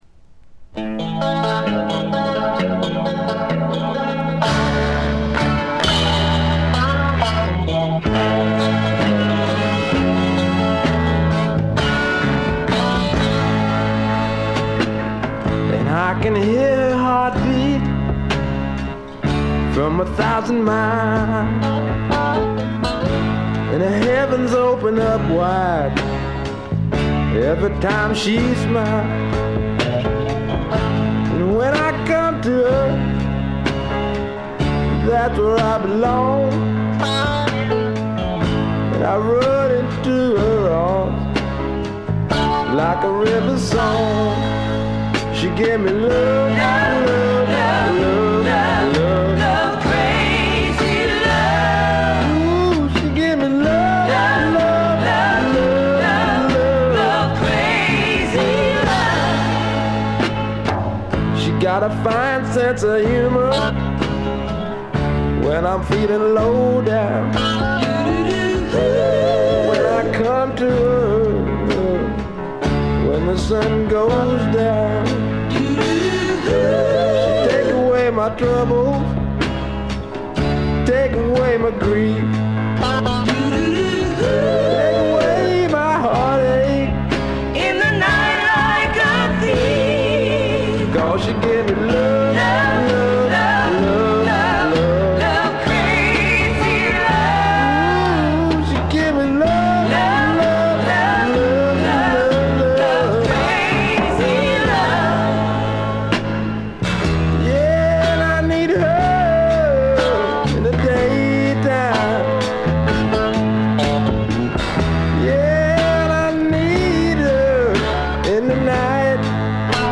ごくわずかなノイズ感のみ。
まさしくスワンプロックの真骨頂。
試聴曲は現品からの取り込み音源です。
Vocal, Guitar, Keyboards